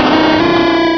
-Replaced the Gen. 1 to 3 cries with BW2 rips.
noctowl.aif